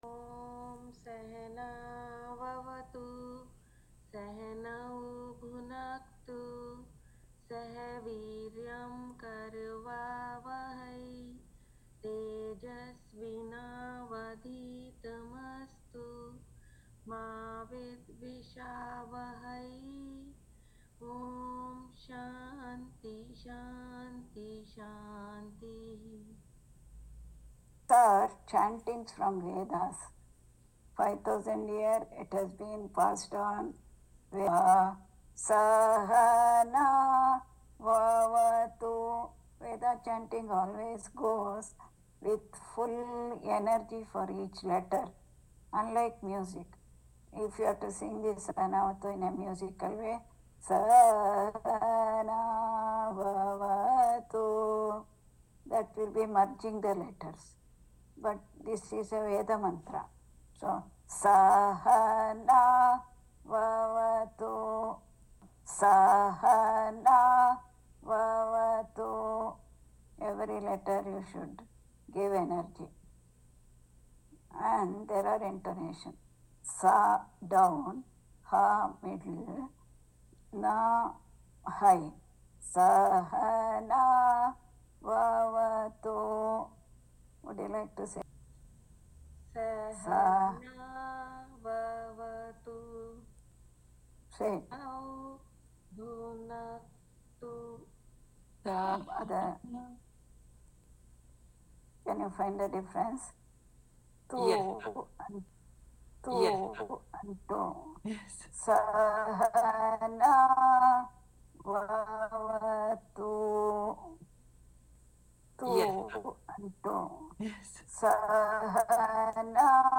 Vedic chanting